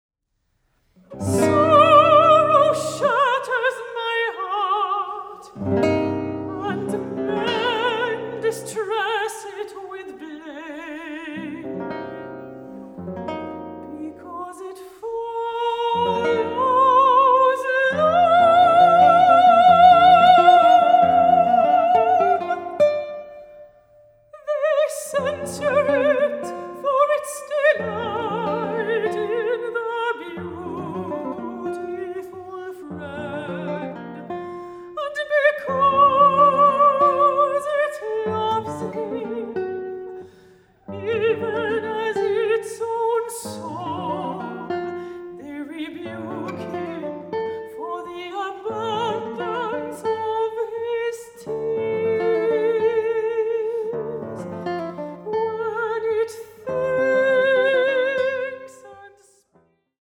Guitar
Vocals